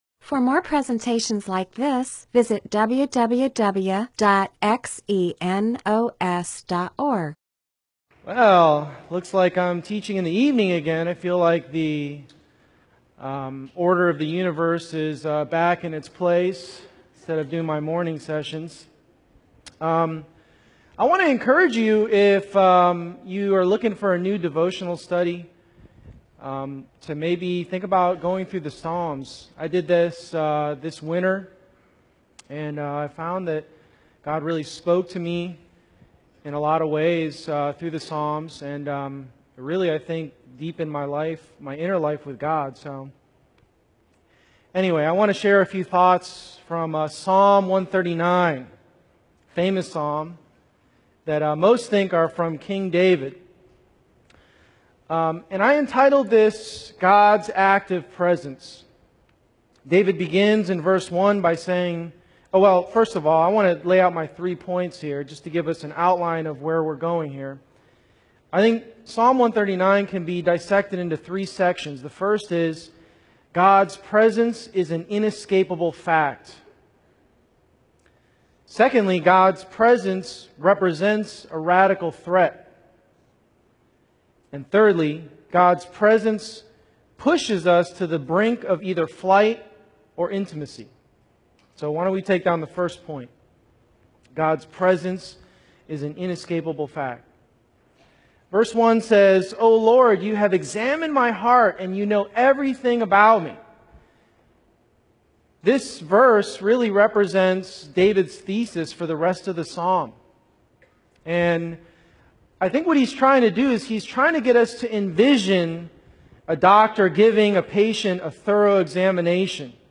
MP4/M4A audio recording of a Bible teaching/sermon/presentation about Psalms 139.